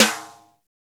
Index of /90_sSampleCDs/Roland L-CD701/KIT_Drum Kits 5/KIT_Tiny Kit
SNR R8 RIM01.wav